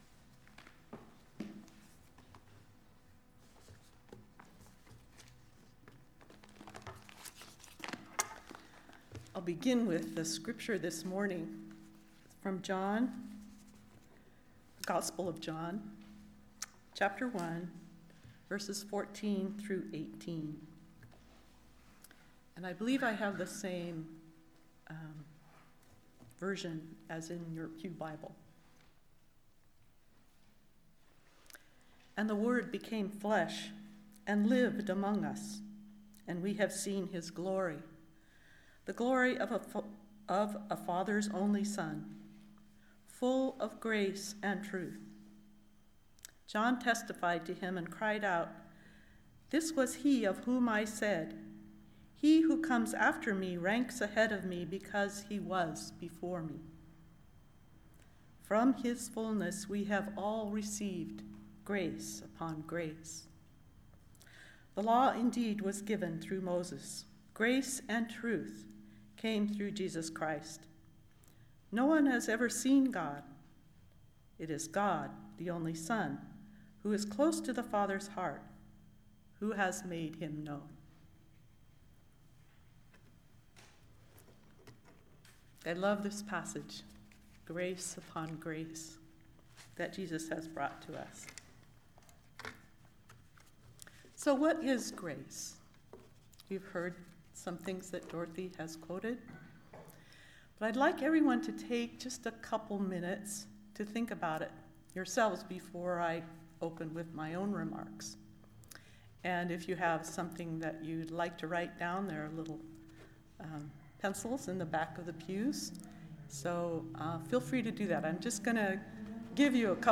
Listen to, “Grace,” the most recent message from Sunday worship at Berkeley Friends Church.